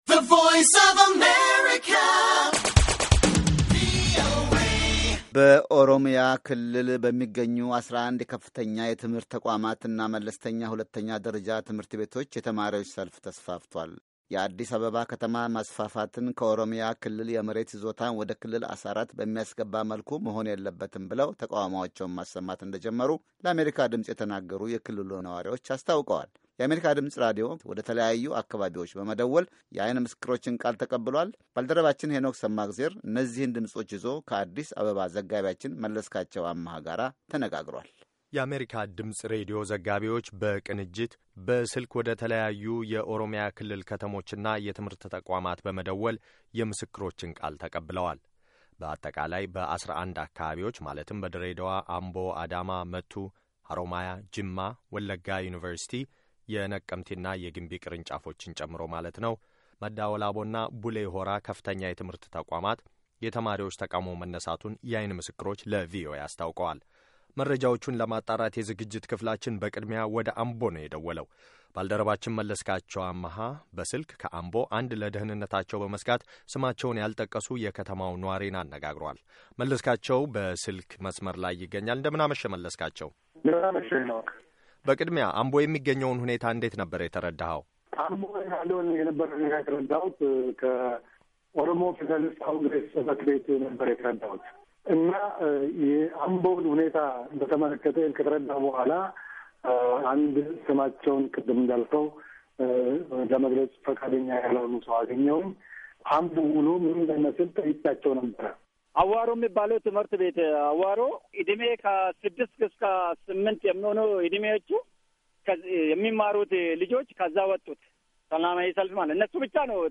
የአሜሪካ ድምጽ ሬድዮ ወደተለያዩ አካባቢዎች በመደወል የዐይን ምሥክሮችን ቃል ተቀብሏል።